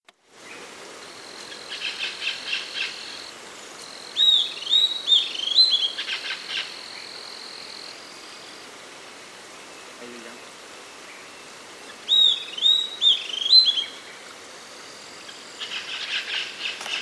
Estalador (Corythopis delalandi)
Nome em Inglês: Southern Antpipit
Fase da vida: Adulto
Localidade ou área protegida: Puerto Iguazú
Condição: Selvagem
Certeza: Gravado Vocal